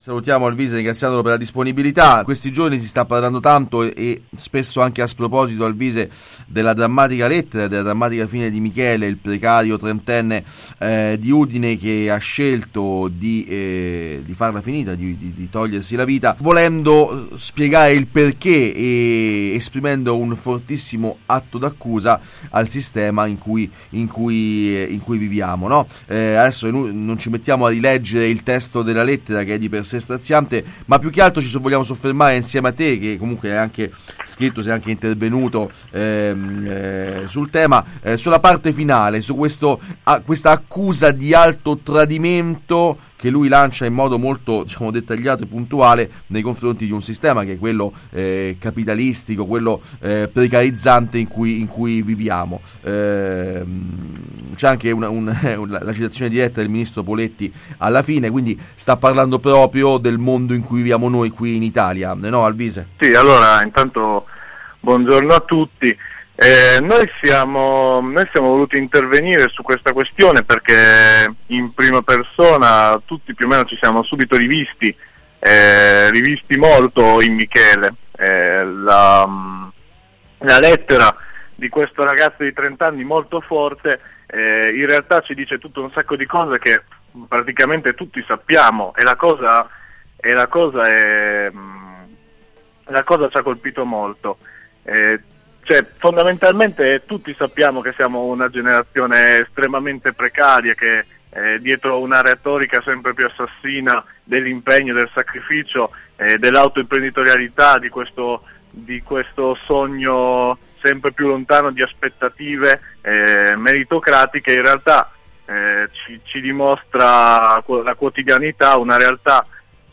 O schiavi o morti? L’alternativa è la reazione collettiva. Intervista con Noi Restiamo.